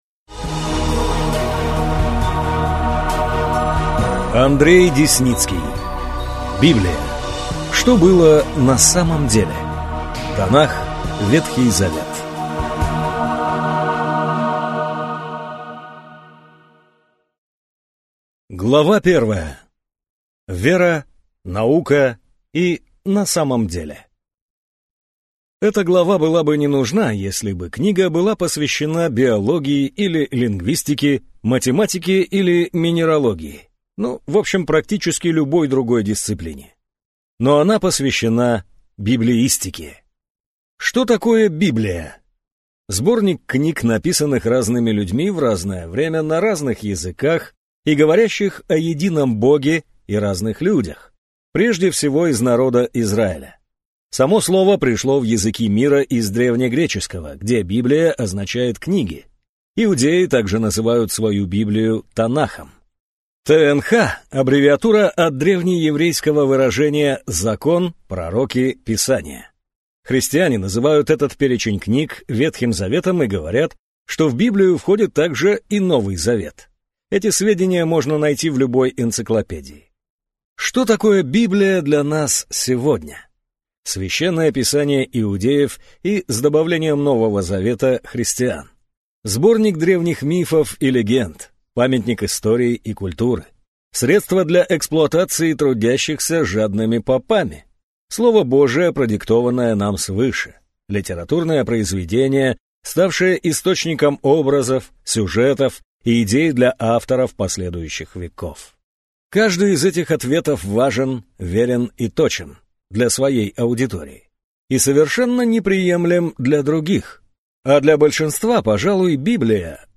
Aудиокнига Библия: Что было «на самом деле»?